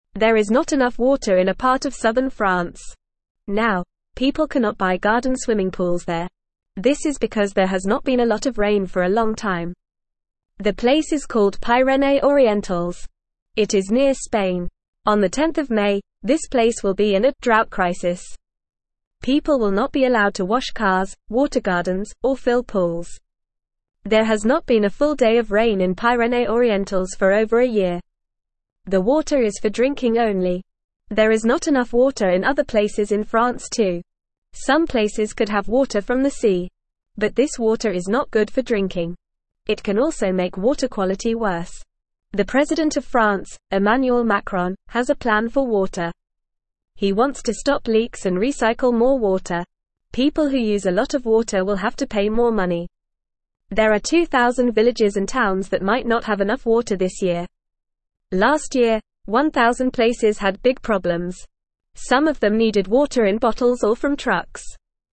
Fast
English-Newsroom-Beginner-FAST-Reading-No-Swimming-Pools-in-Dry-French-Area.mp3